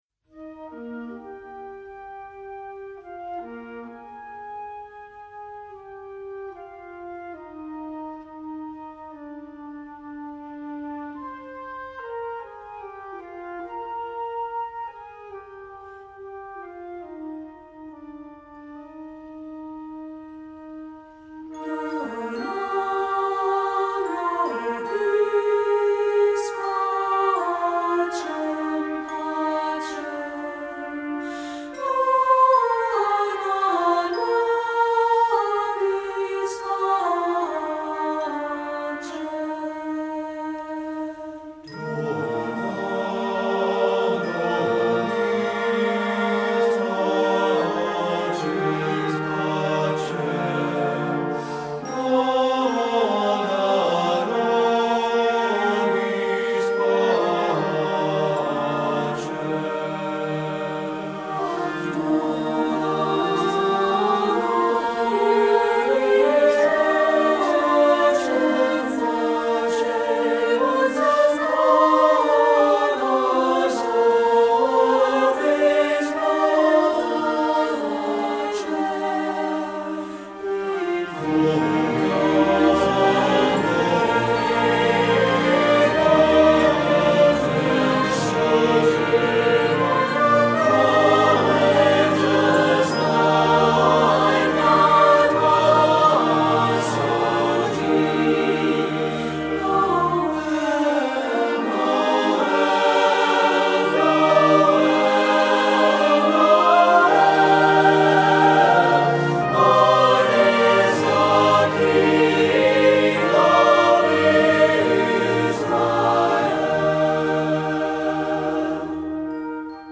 Composer: Traditional Canon
Voicing: SATB